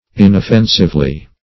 -- In"of*fen"sive*ly, adv. -- In"of*fen"sive*ness, n.